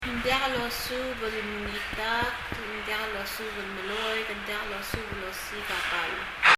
発音　　英訳：